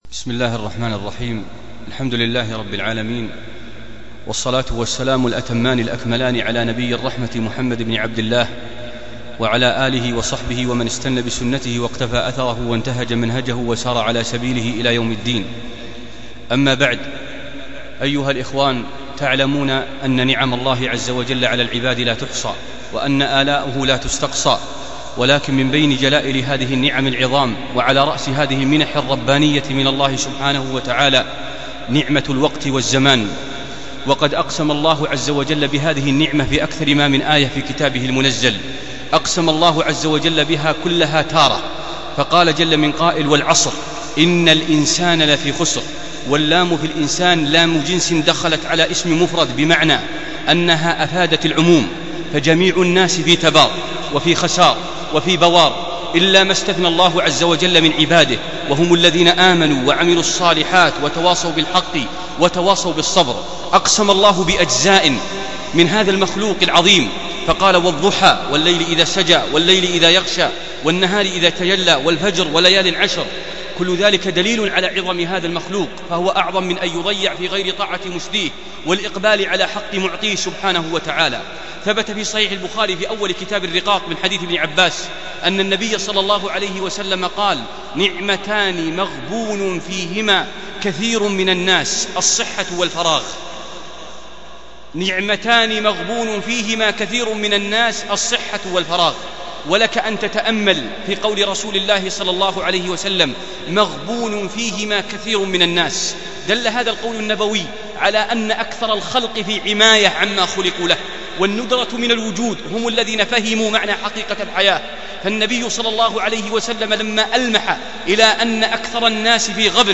موعظة بليغة عن تعظيم الوقت